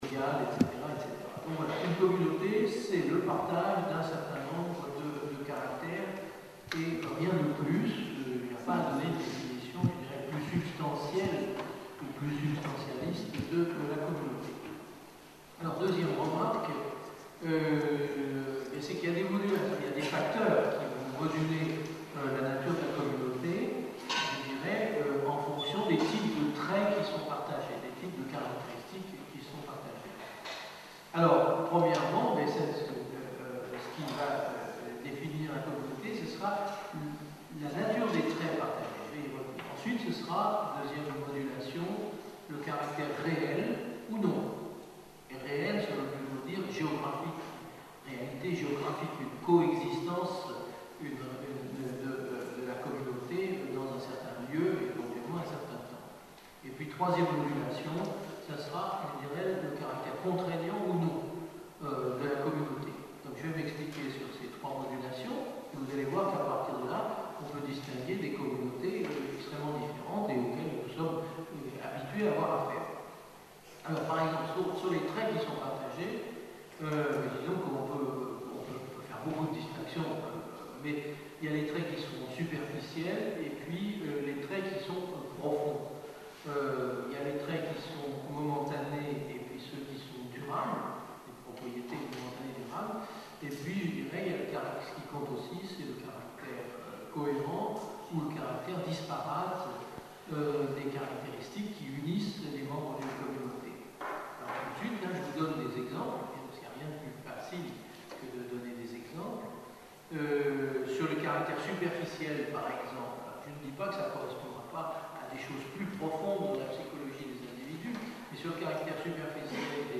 Une conférence de l'UTLS au Lycée en partenariat avec Les mercredis de Créteil (94 Créteil)